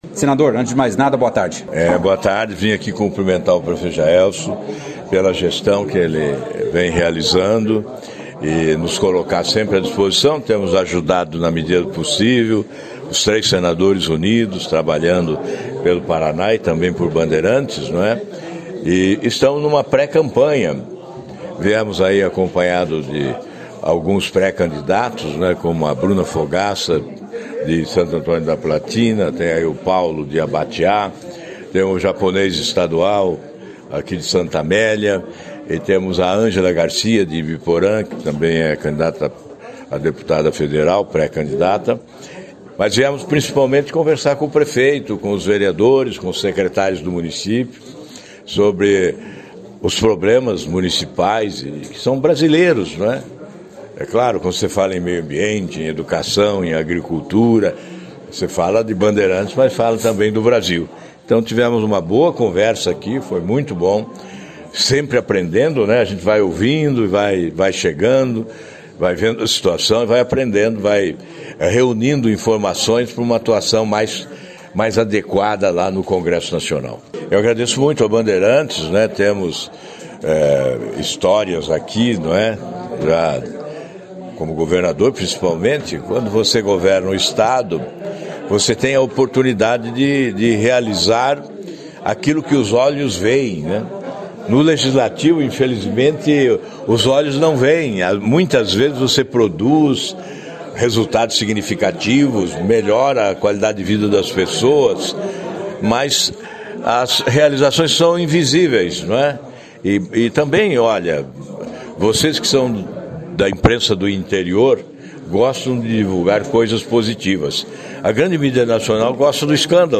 O senador e ex-governador Alvaro Dias, esteve em Bandeirantes na manhã desta terça-feira, 19/07, onde foi recebido pelo prefeito Jaelson Matta e assessores. Na oportunidade o senador, pré-candidato a reeleição ao Senado Federal, falou com a nossa equipe sobre o trabalho realizado ao longo dos anos, da sua ligação com a cidade, além de destacar a possível manutenção da aliança no estado, com o governador Ratinho Junior, que a trabalhou pela eleição dois senadores em 2018, Flávio Arns e Oriovisto Guimarães, ambos pelo Podemos.